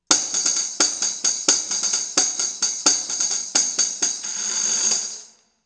tambrine.wav